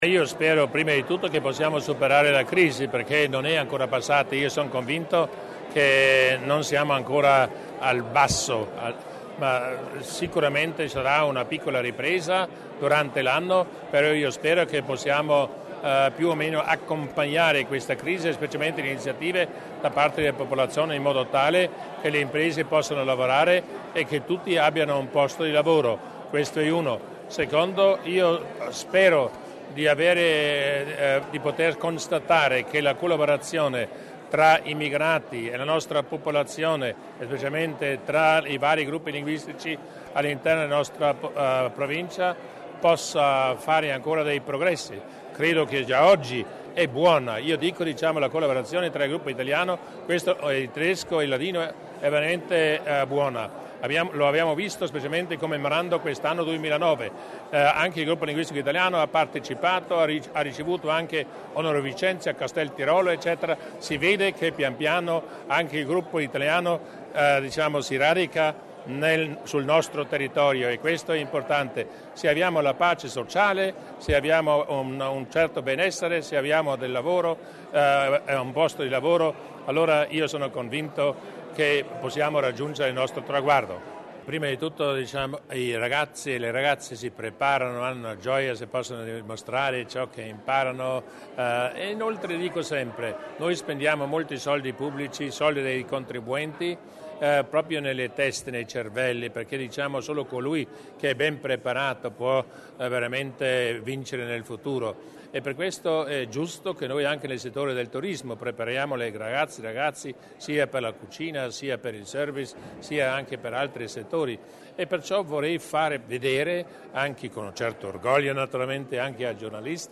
Ricevimento di Natale con la stampa: intervista del Presidente Durnwalder